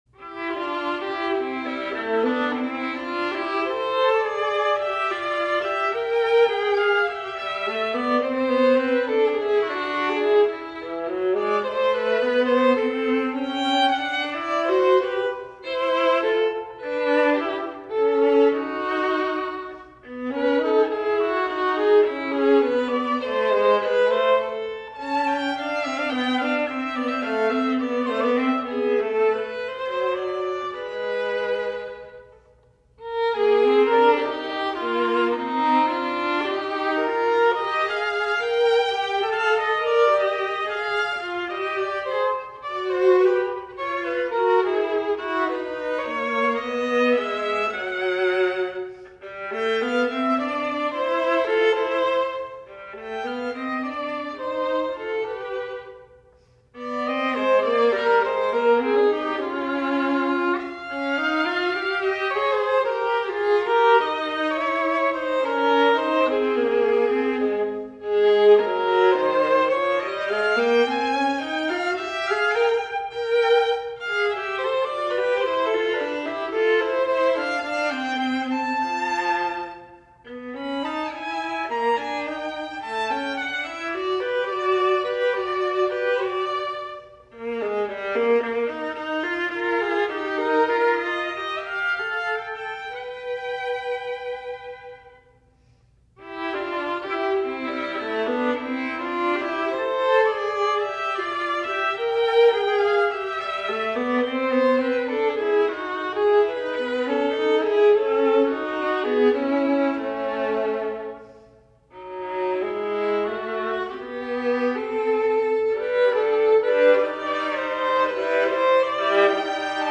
Voicing: String Duo